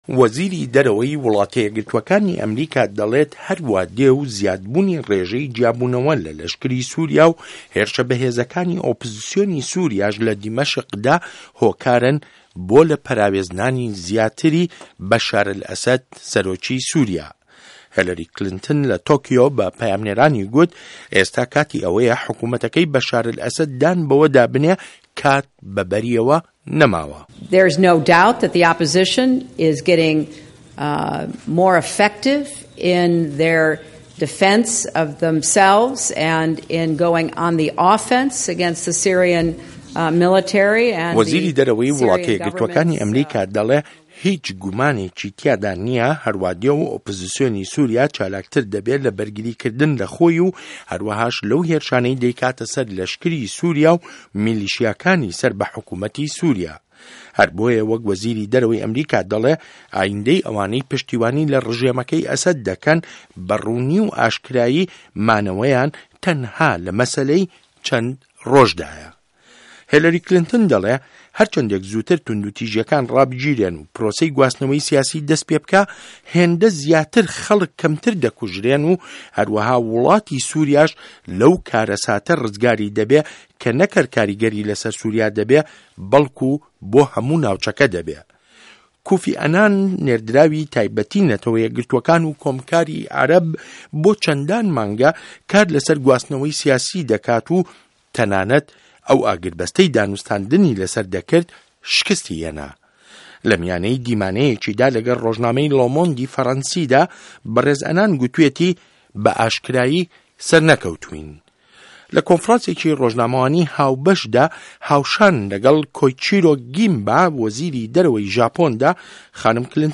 ڕاپۆرتی ئه‌مریکا و سوریا